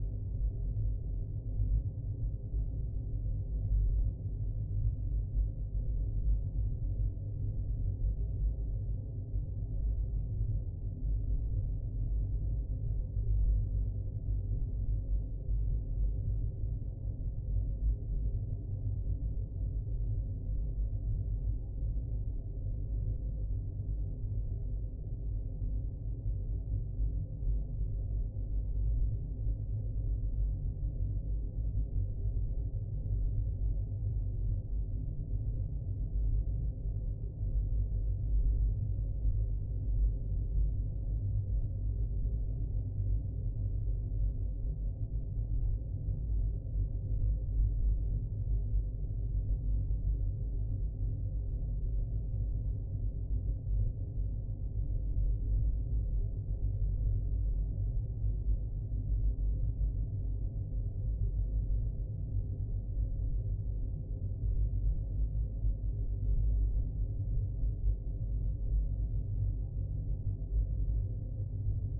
Sci-Fi Sounds / Hum and Ambience
Low Rumble Loop 5.wav